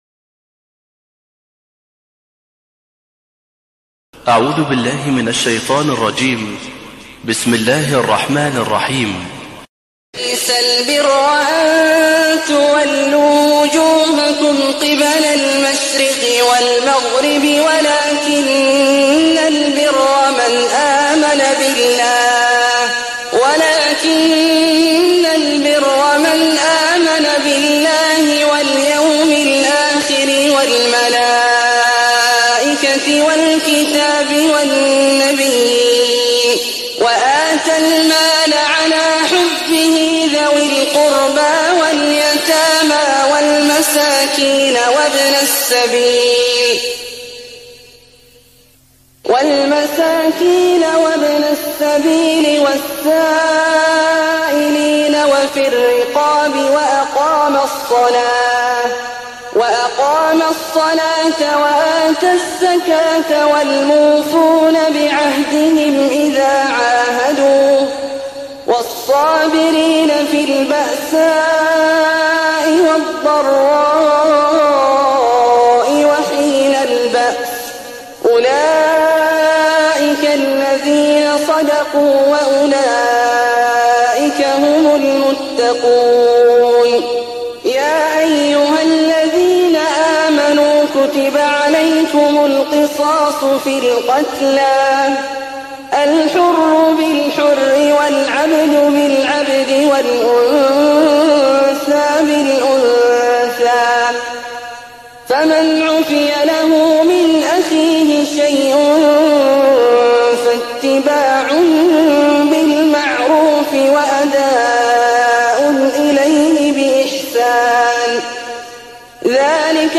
تهجد ليلة 22 رمضان 1419هـ من سورة البقرة (177-252) Tahajjud 22nd night Ramadan 1419H from Surah Al-Baqara > تراويح الحرم النبوي عام 1419 🕌 > التراويح - تلاوات الحرمين